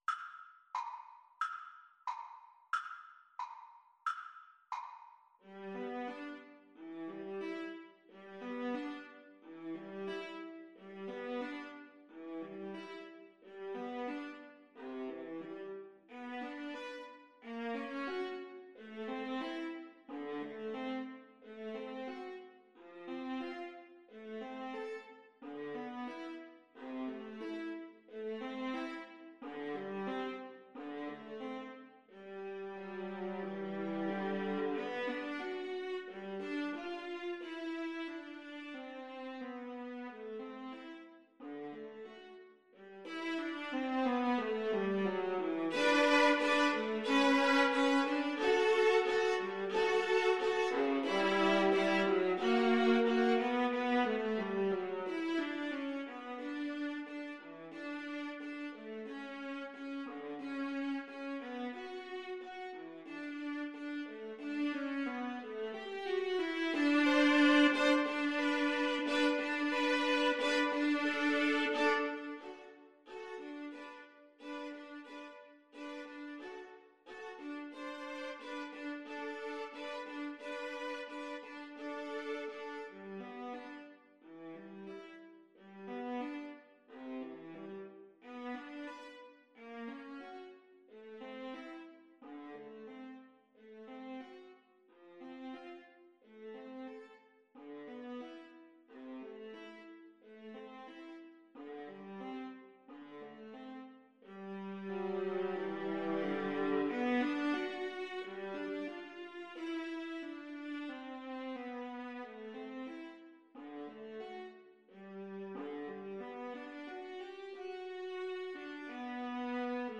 Play (or use space bar on your keyboard) Pause Music Playalong - Player 1 Accompaniment reset tempo print settings full screen
~ = 100 Allegretto con moto =90
G major (Sounding Pitch) (View more G major Music for Viola Duet )
Classical (View more Classical Viola Duet Music)